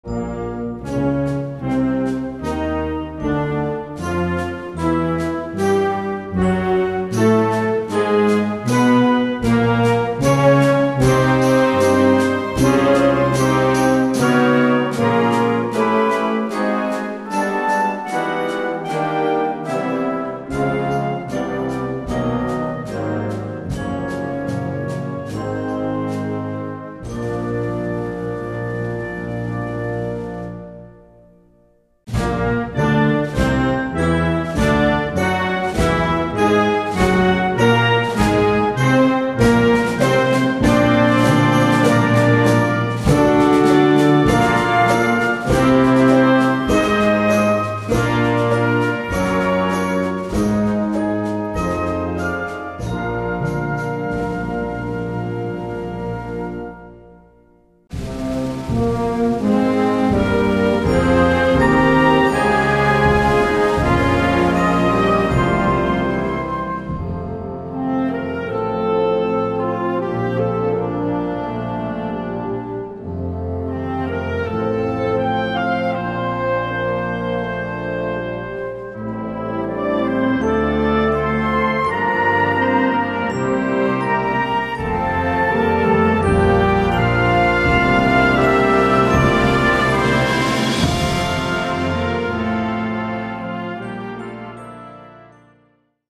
Etude pour Harmonie/fanfare